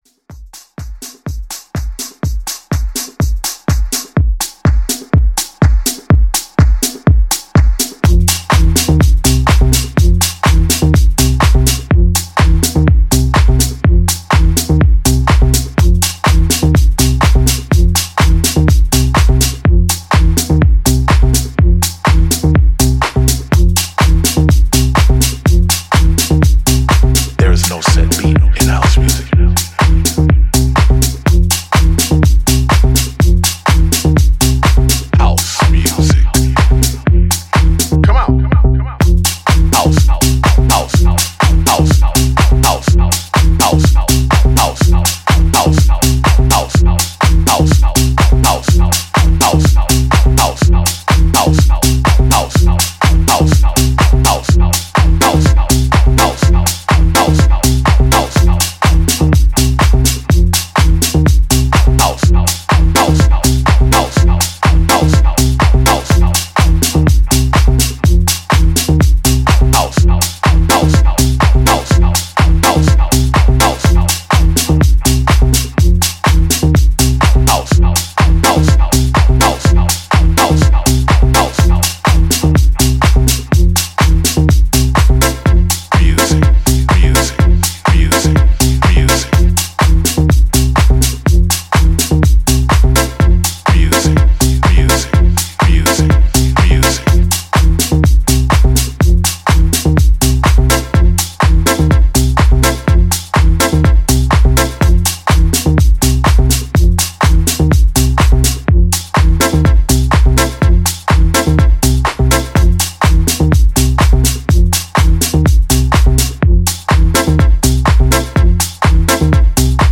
ジャンル(スタイル) HOUSE / RE-EDIT